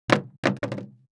Index of /traerlab/AnalogousNonSpeech/assets/stimuli_demos/jittered_impacts/large_styrofoam_longthin_foamroller